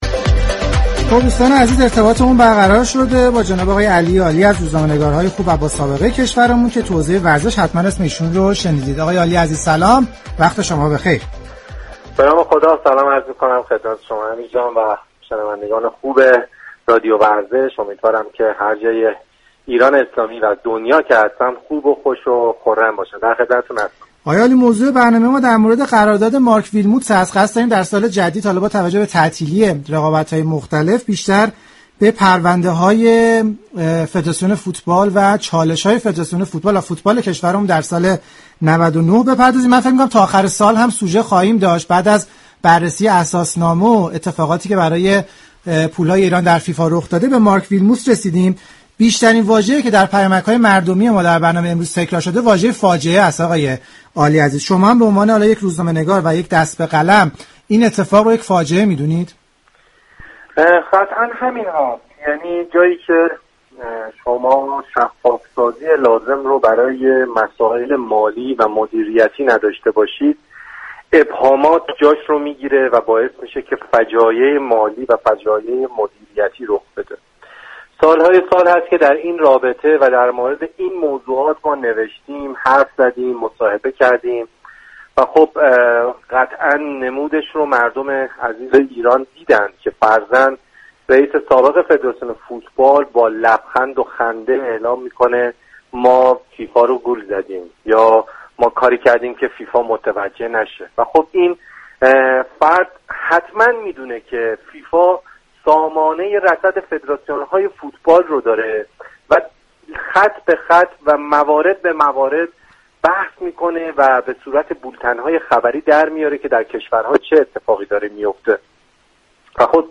برنامه زنده